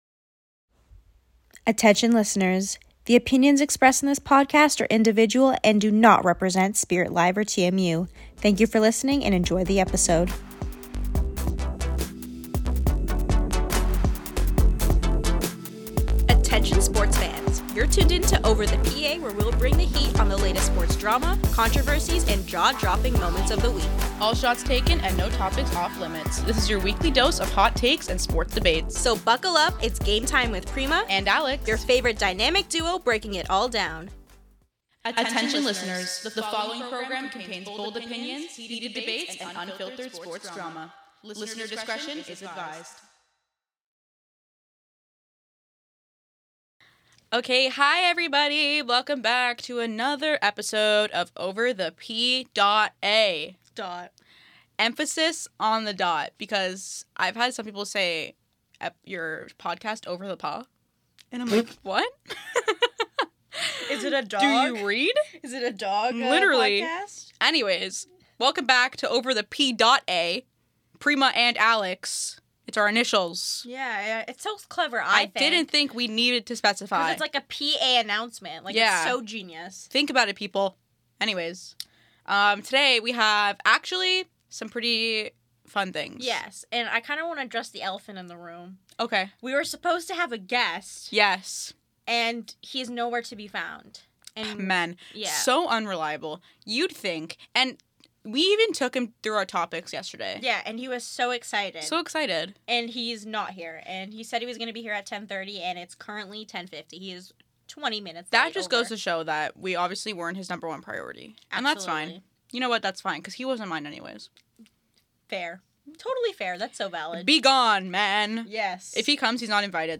another chaotic episode, but this time with a twist… a guest!